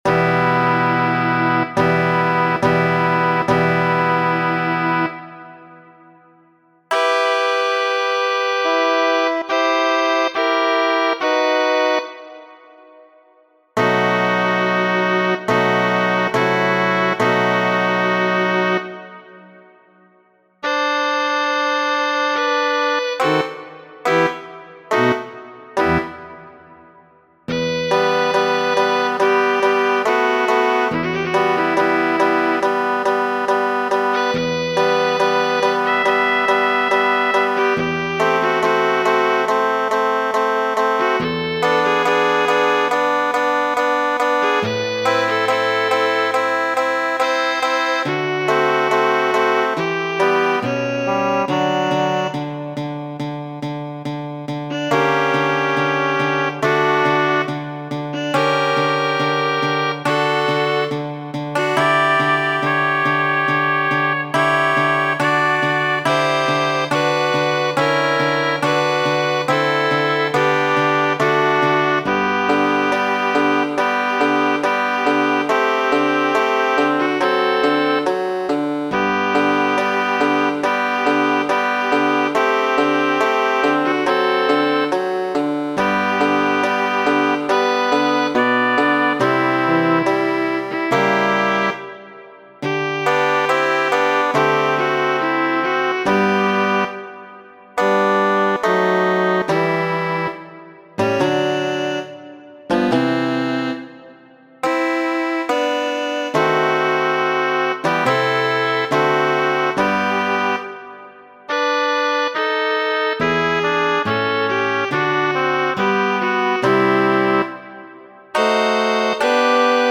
Muziko: